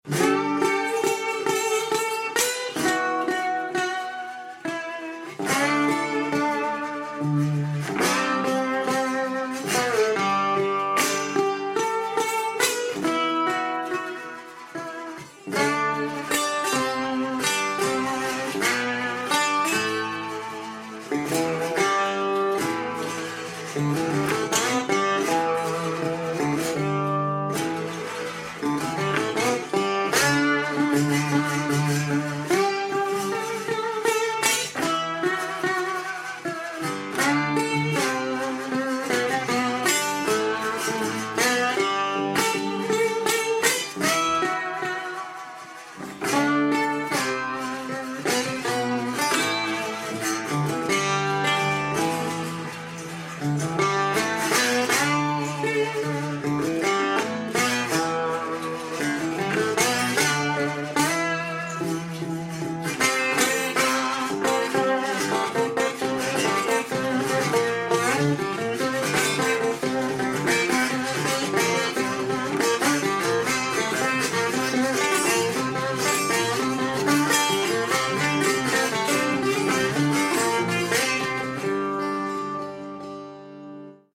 Akustische Gitarren